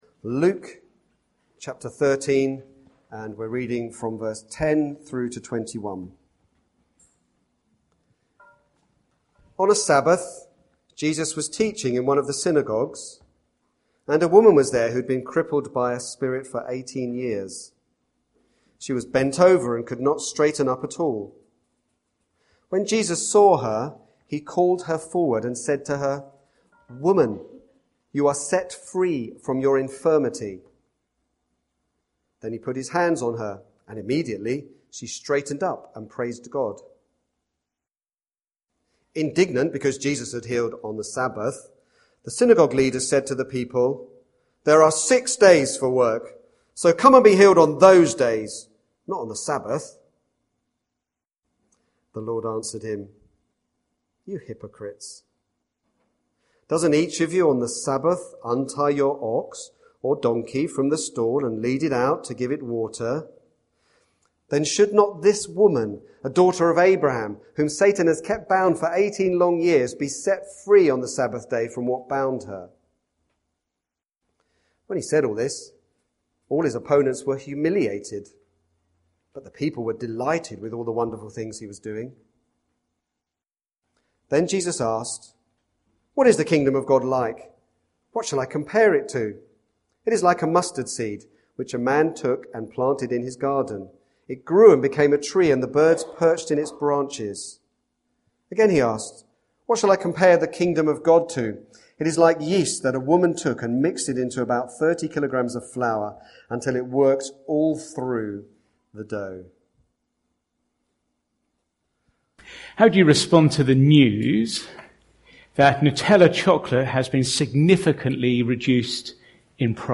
Sermon starts about 50 mins into the recording Series: Luke (2018)